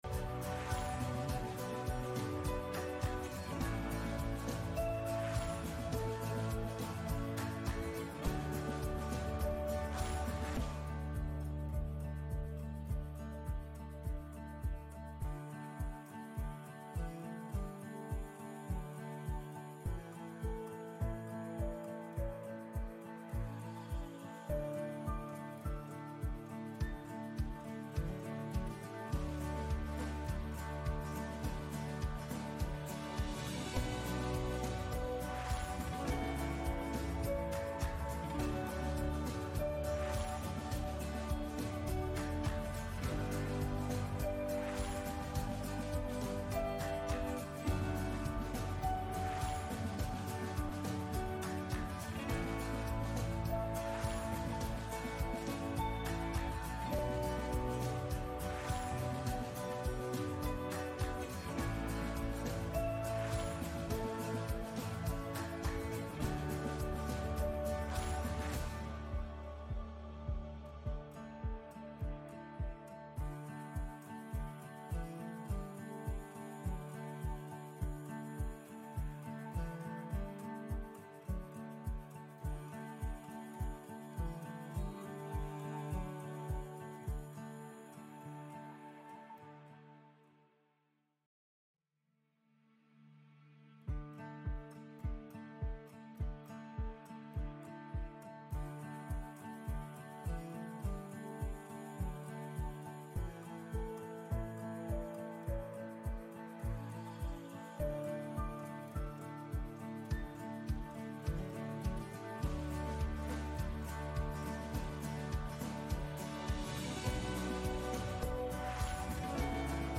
Gottesdienst am 18. Januar aus der Christuskirche Altona on 18-Jan-26-09:16:47